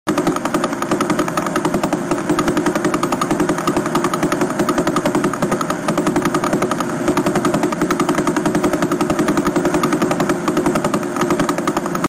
The sound of the Vela sound effects free download
The sound of the Vela pulsar.